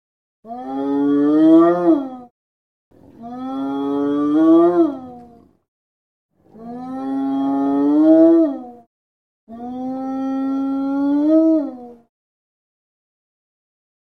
На этой странице собраны разнообразные звуки гиены: от характерного смеха до агрессивного рычания.
Звук стонущей пятнистой гиены